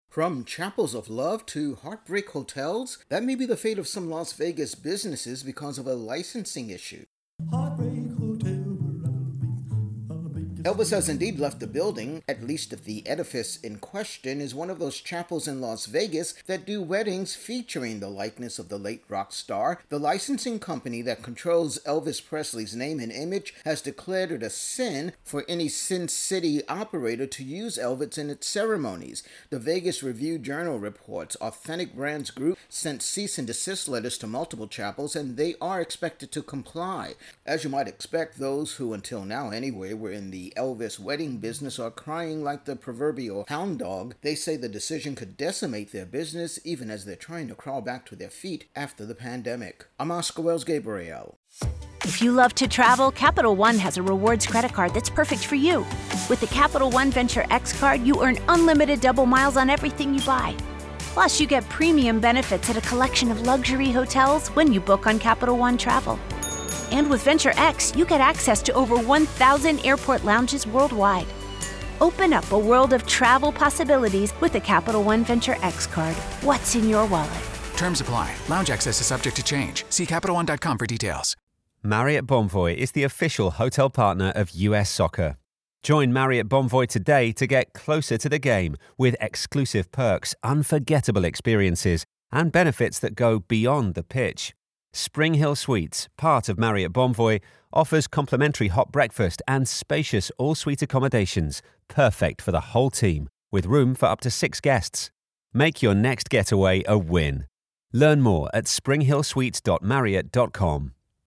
Intro+wrap on Elvis-themed chapels in Vegas told to stop